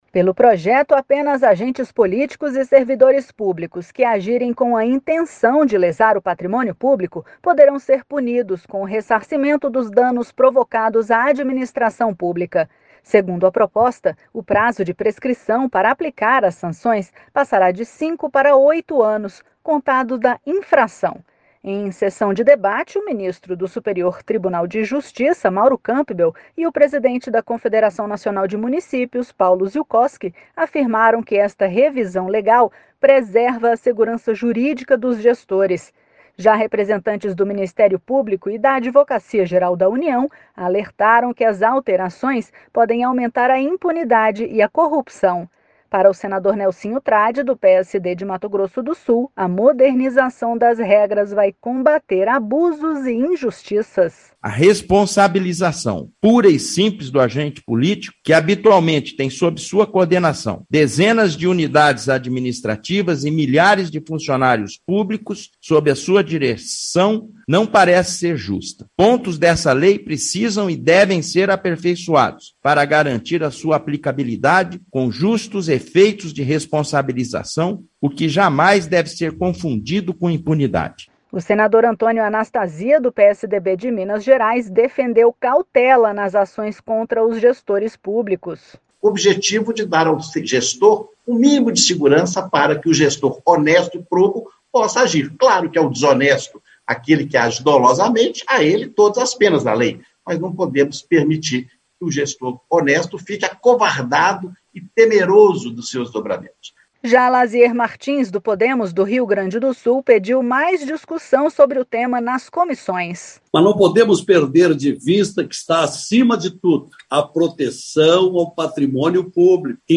Sessão temática